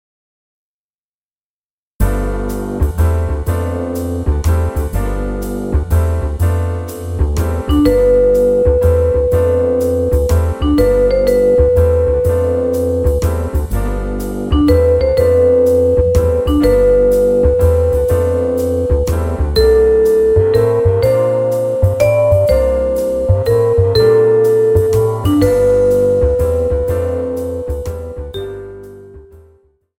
Saxophone Alto